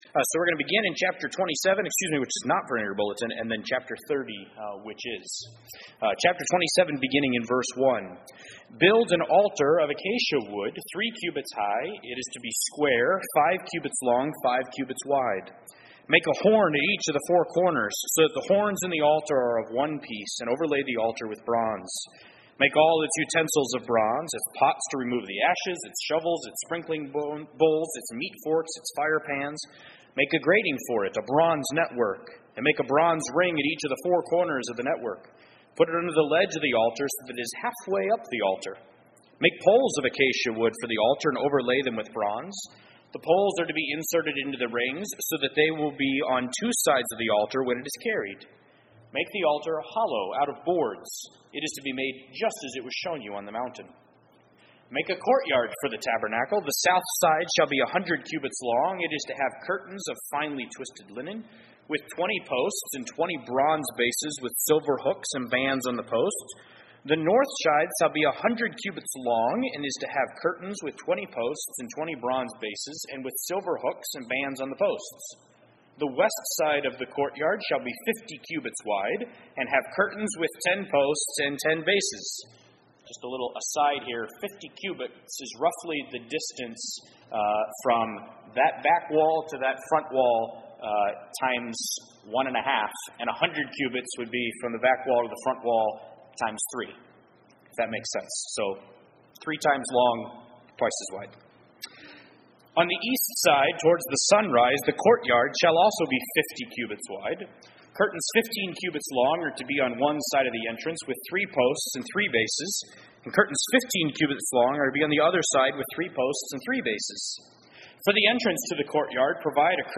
Exodus 30:17-38 Service Type: Sunday Evening %todo_render% « Matthew 24:1-36 Exodus 27